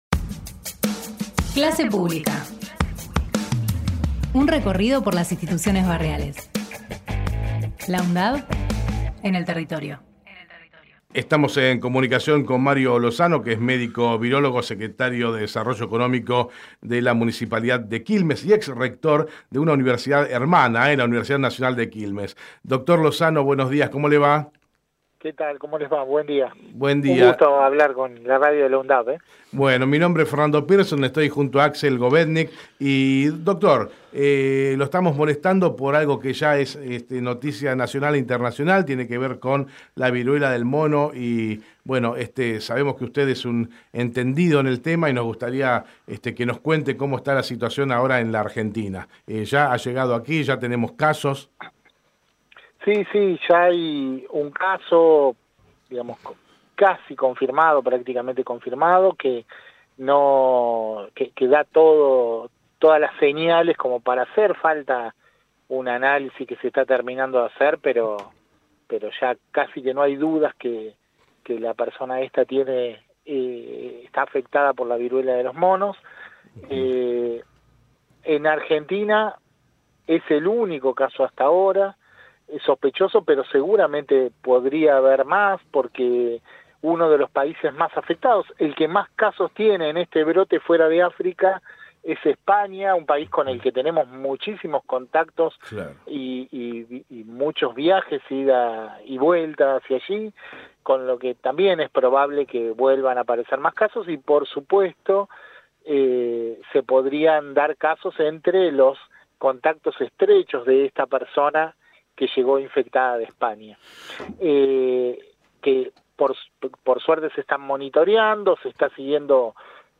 Mario Lozano en Hacemos PyE Texto de la nota: Compartimos la entrevista realizada en Hacemos PyE a Mario Lozano médico virólogo del CONICET, Secretario de Desarrollo Económico del Municipio de Quilmes y ex rector de la UNQ. Conversamos sobre la viruela del mono, las consecuencias de la enfermedad y el primer caso en la Argentina.